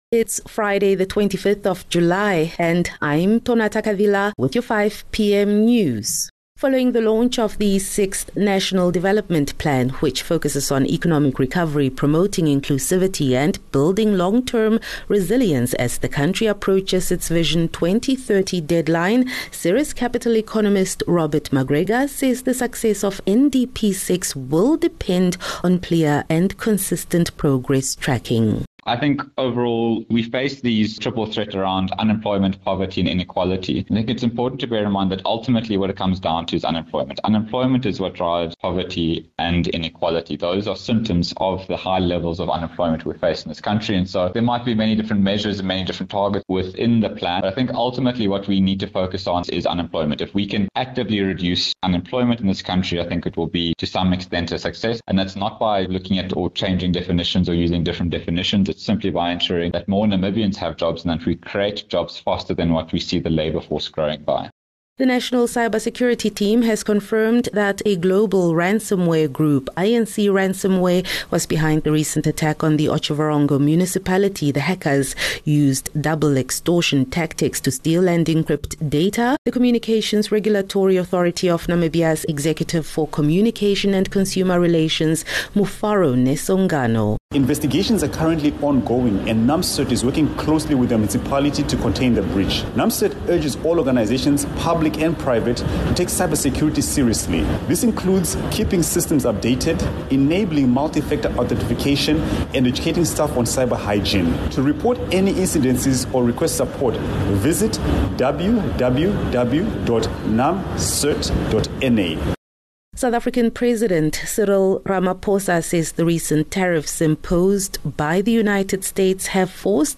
25 Jul 25 July - 5 pm news